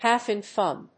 half+in+fun.mp3